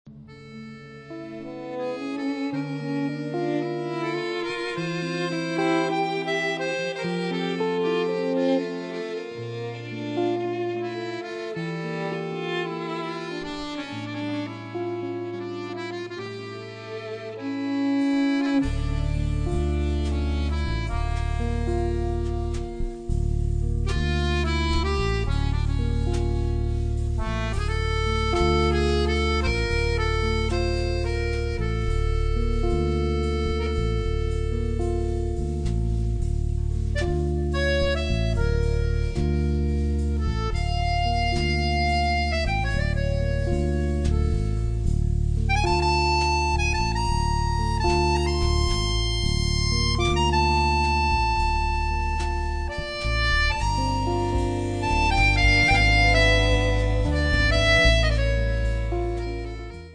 Accordeon
Violino
Chitarra elettrica
Basso
Batteria e percussioni
Un percorso impregnato di Mediterraneo